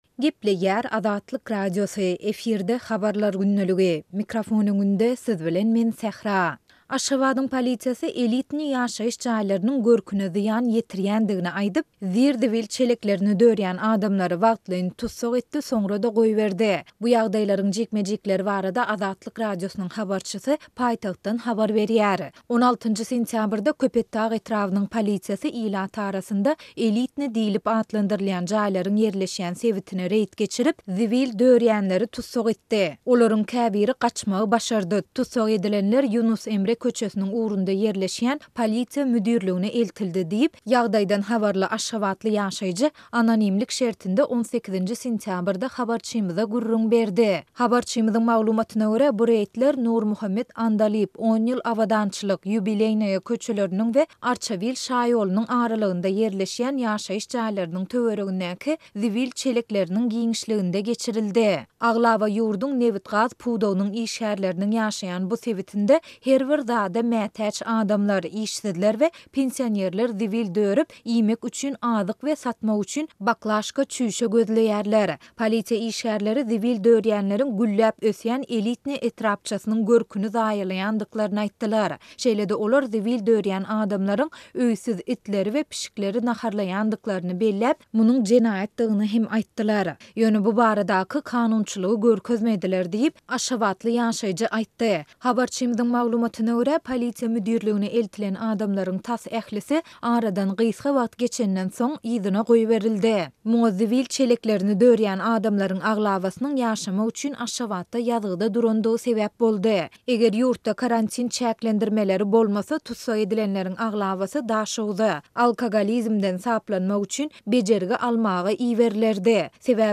Aşgabadyň polisiýasy "elitnyý" ýaşaýyş jaýlarynyň görküne zyýan ýetirýändiklerini aýdyp, zir-zibil çeleklerini dörýän adamlary wagtlaýyn tussag etdi, soňra-da goýberdi. Bu ýagdaýlaryň jikme-jikleri barada Azatlyk Radiosynyň habarçysy türkmen paýtagtyndan habar berýär.